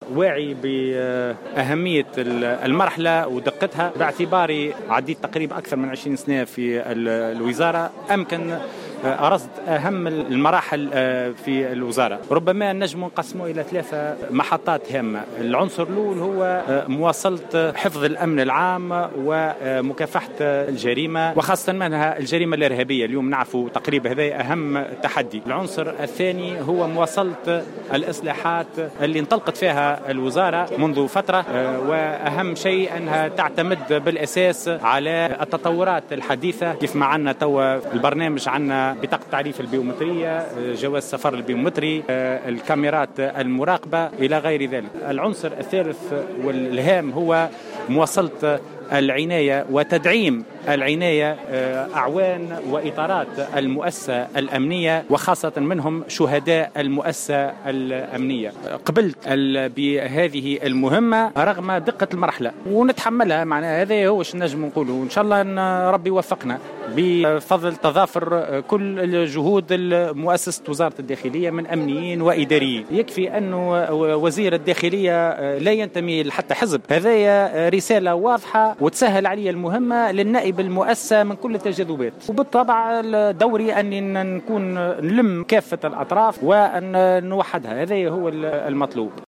وزير الداخلية في أول تصريح له بعد حصوله على ثقة النواب